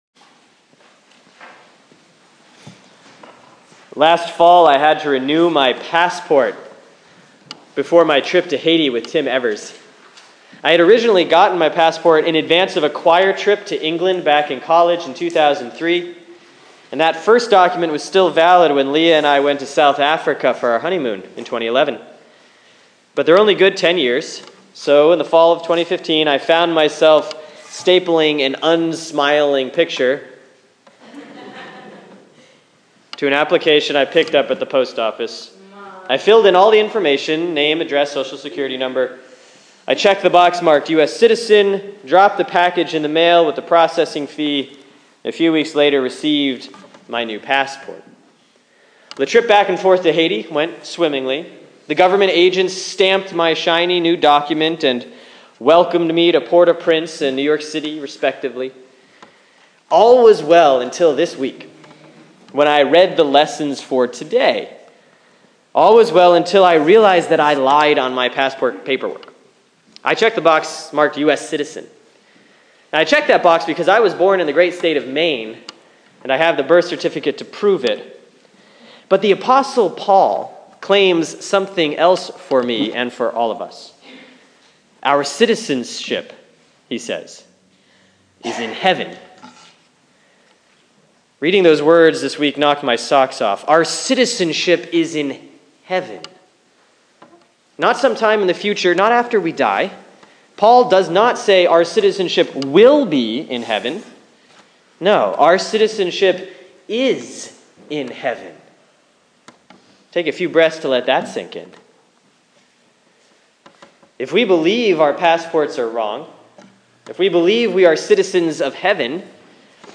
Sermon for Sunday, February 21, 2016 || Lent 2C || Philippians 3:17–4:1